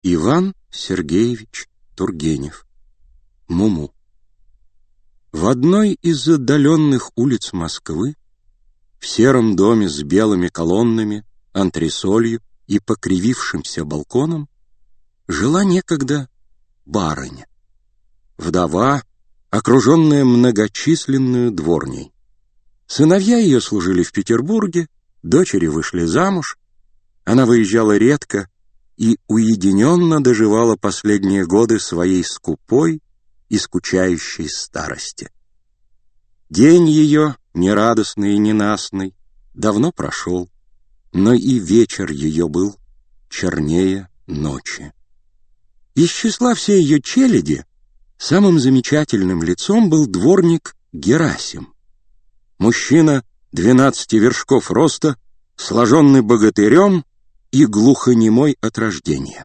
Аудиокнига Муму | Библиотека аудиокниг